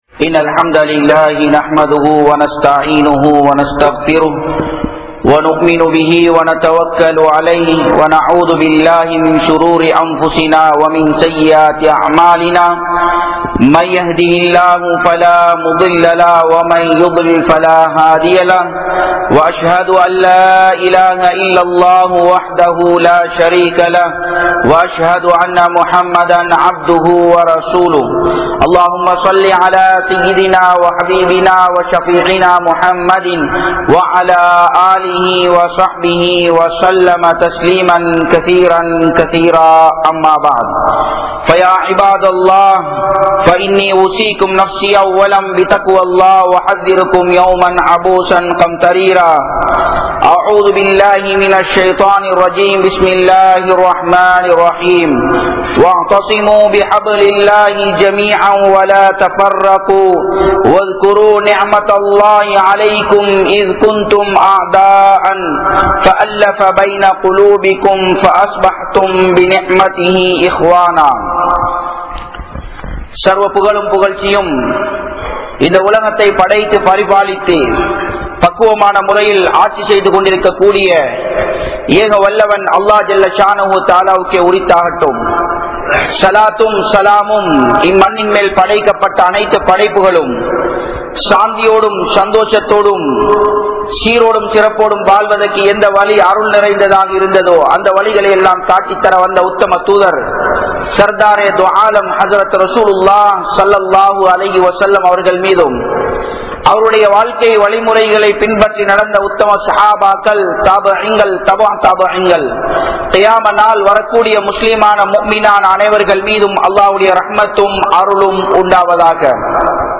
Allah`vin Uthavi Eppoathu Varum? (அல்லாஹ்வின் உதவி எப்போது வரும்?) | Audio Bayans | All Ceylon Muslim Youth Community | Addalaichenai